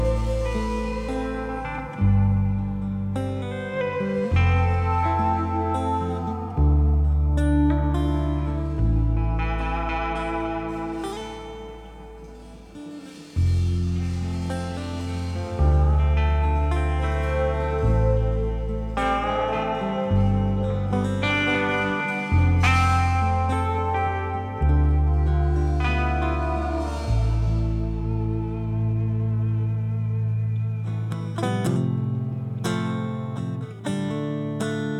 Жанр: Альтернатива
# Alternative